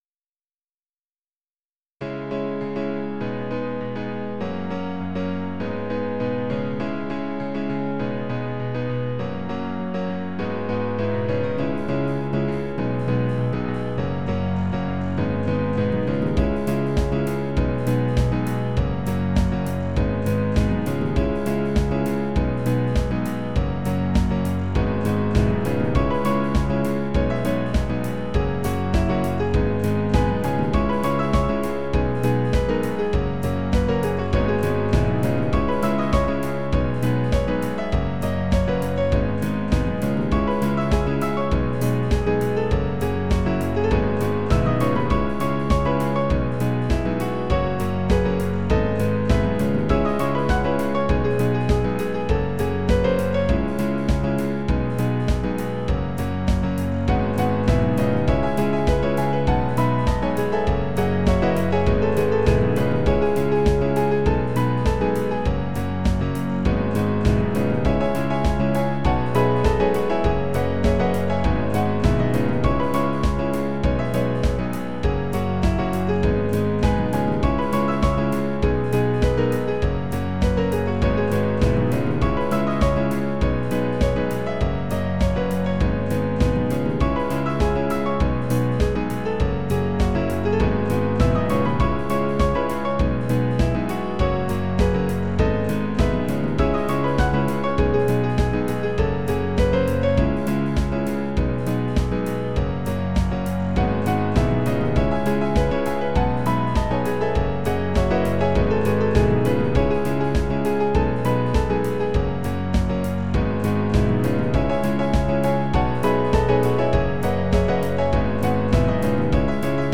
Tags: Quartet, Piano, Guitar, Percussion